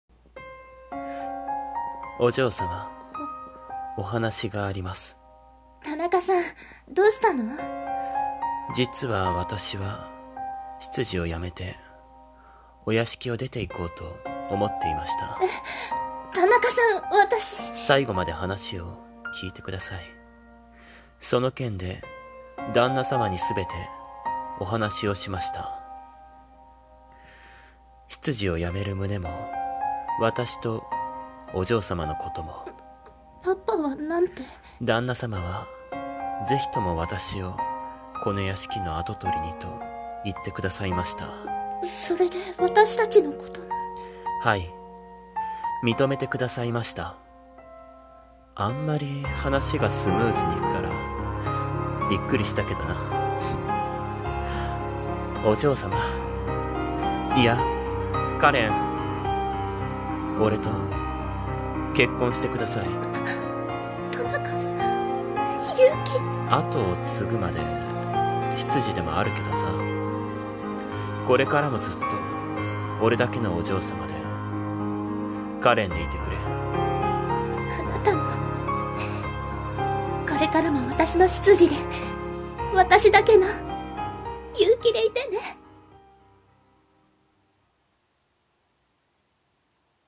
【二人声劇】秘密の恋⑧